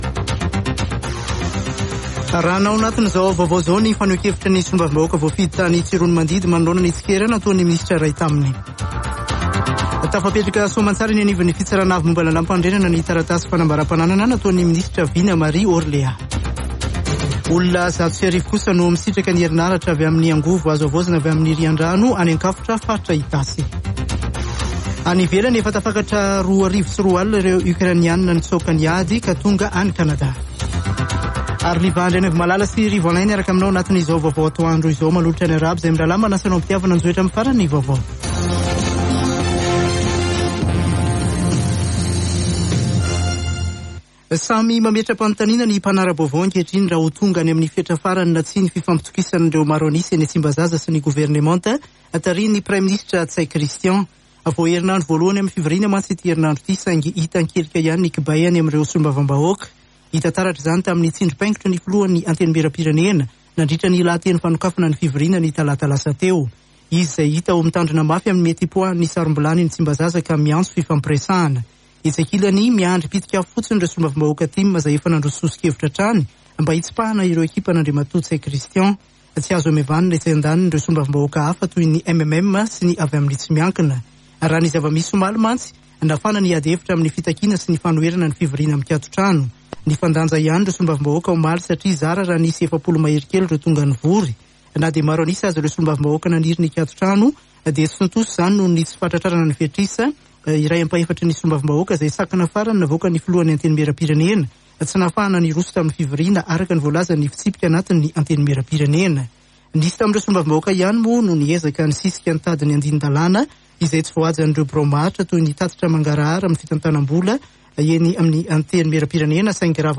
[Vaovao antoandro] Zoma 6 mey 2022